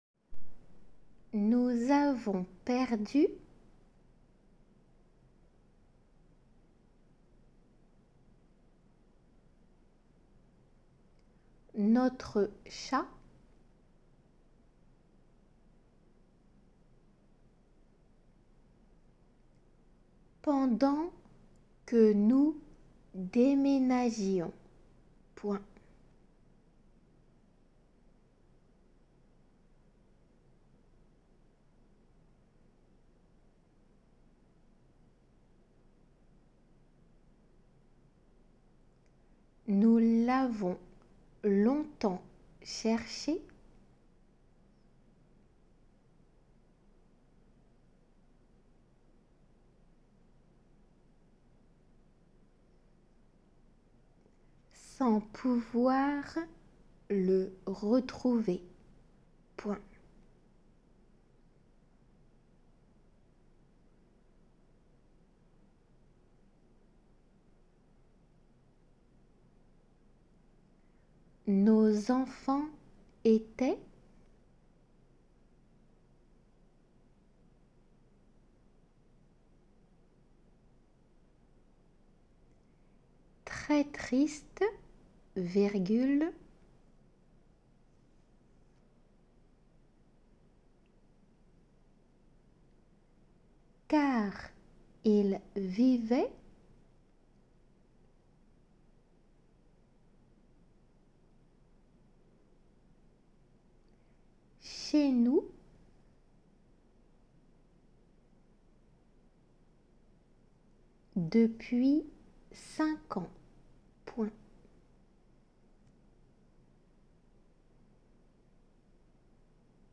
このページではデイクテ用の速さで一回読まれています。